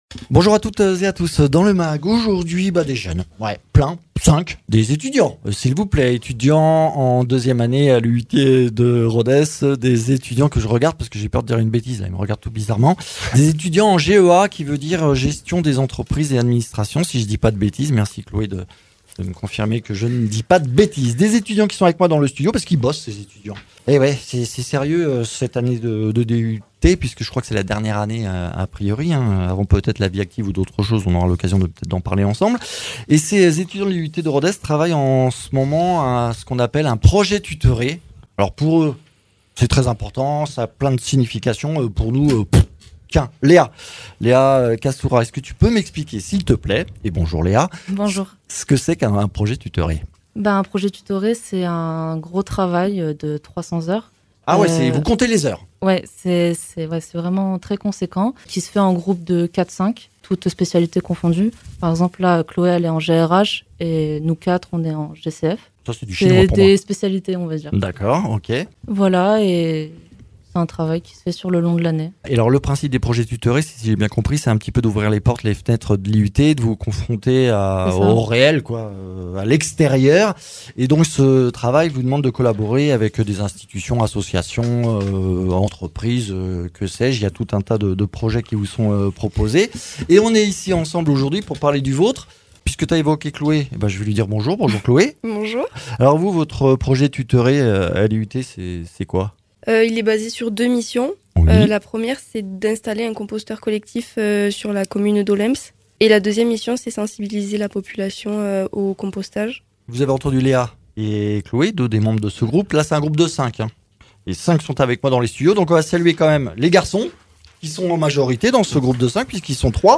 étudiants en 2e année de gestion des entreprises et administration